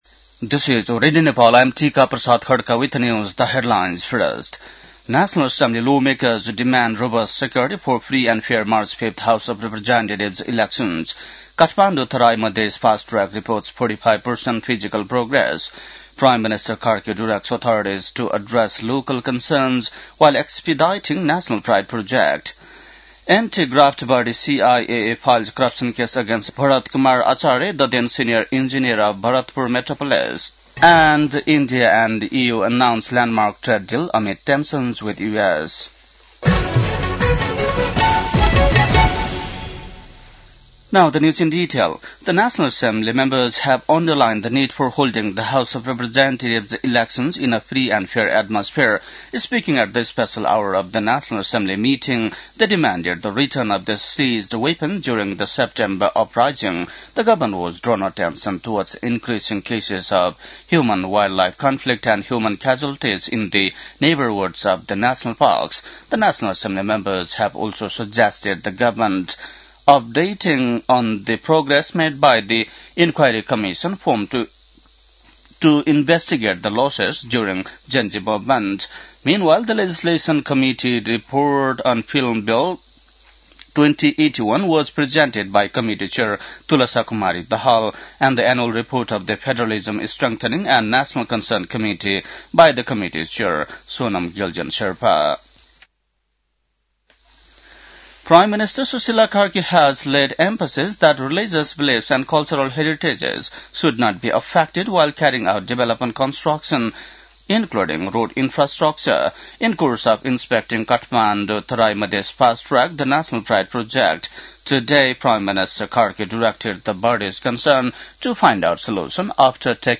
बेलुकी ८ बजेको अङ्ग्रेजी समाचार : १३ माघ , २०८२